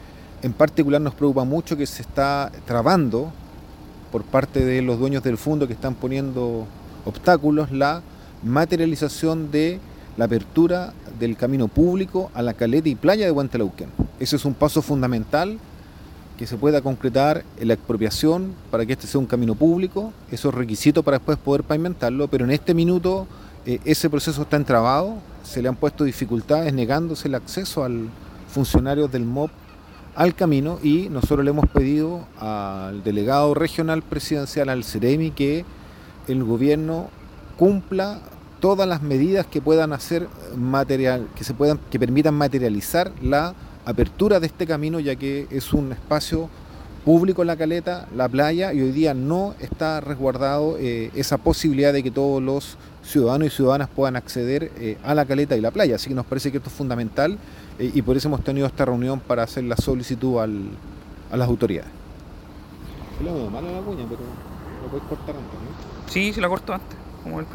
Tras el encuentro, el senador Núñez comentó que